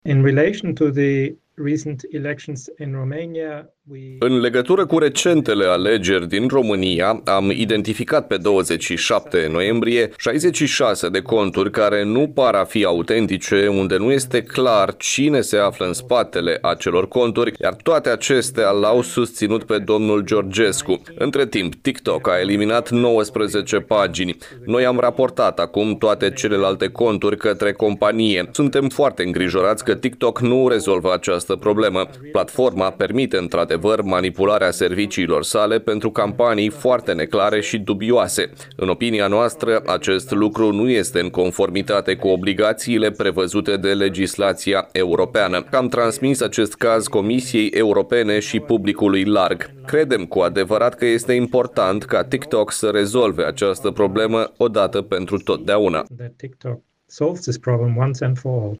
Într-o declarație acordată în exclusivitate pentru Radio Iași